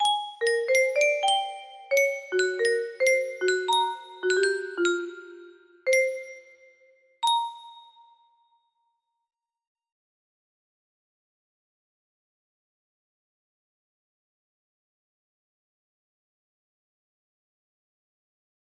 beats music box melody